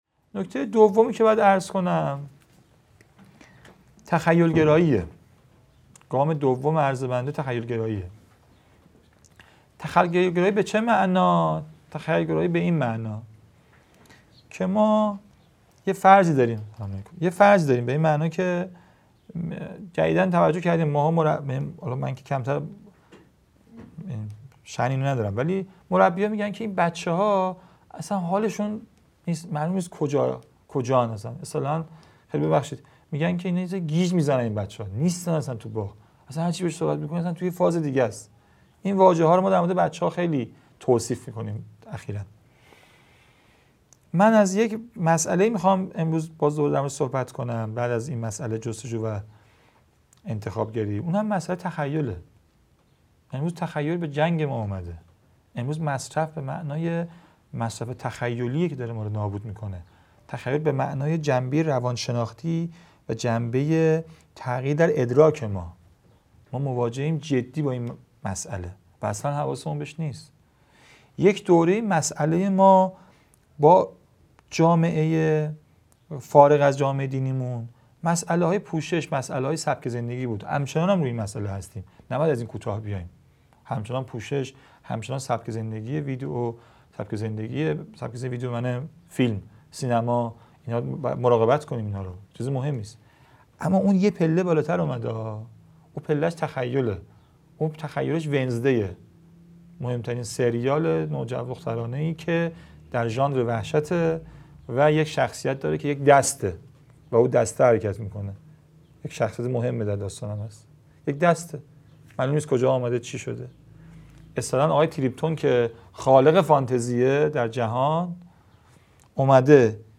گزیده نهم از دومین سلسله نشست‌ های هیأت و نوجوانان
قم - اردبیهشت ماه 1402